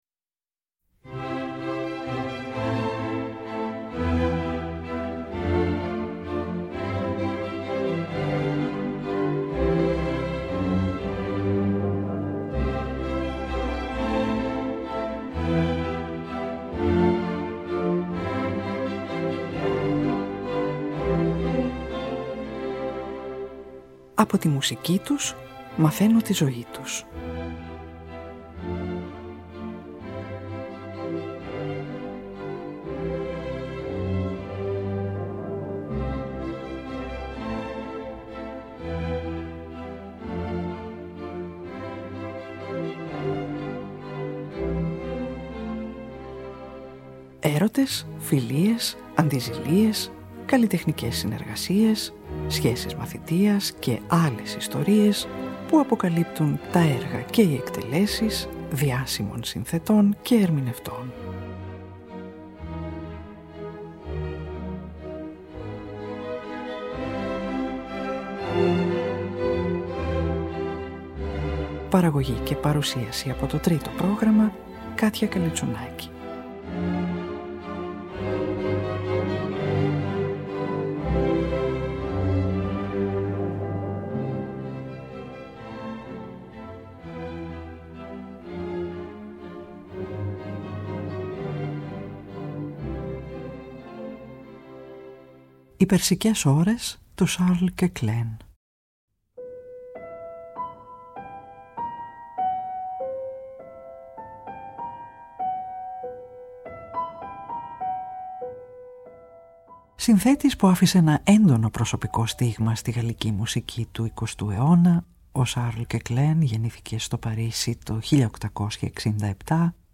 τον πιανιστικό κύκλο
πιανίστας